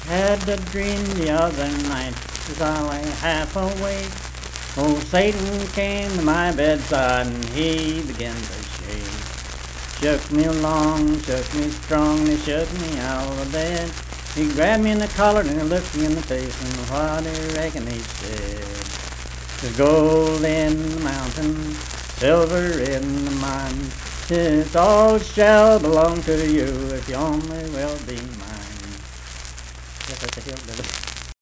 Unaccompanied vocal music
Verse-refrain 1(12). Performed in Jackson, Ohio.
Voice (sung)